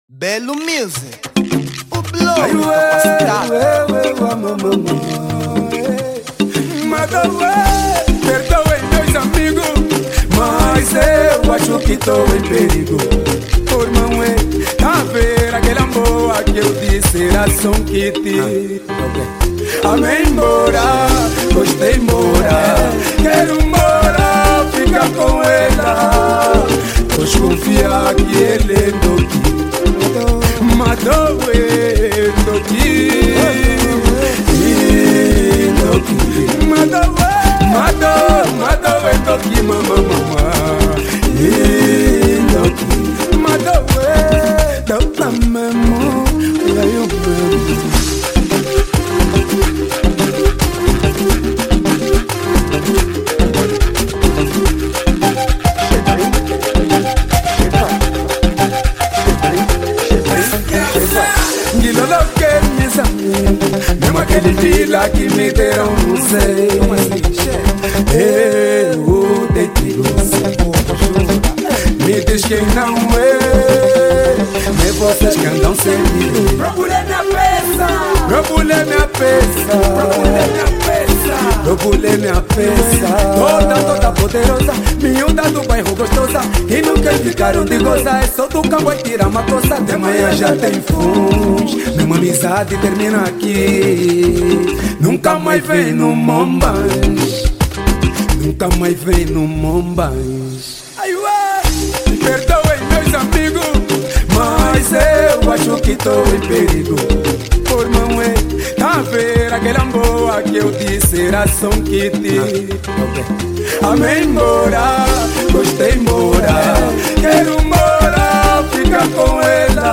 Género : Kuduro